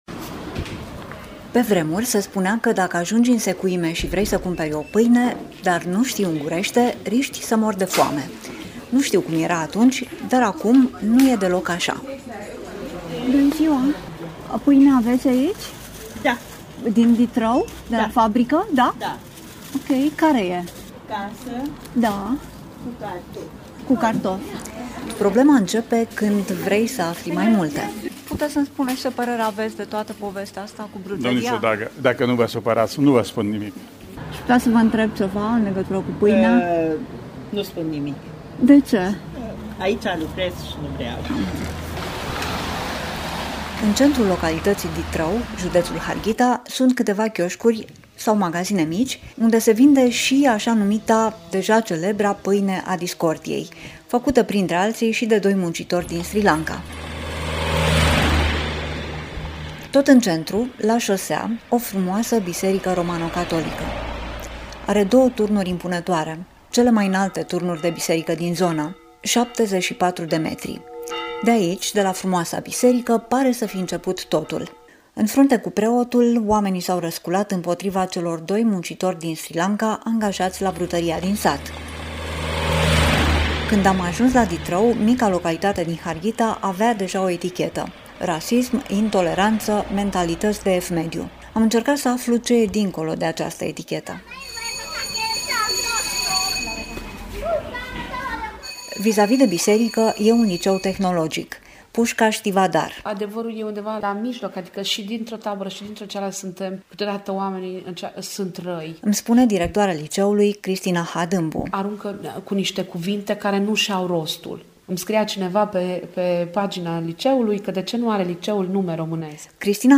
reportaj-ditrau-unguri.mp3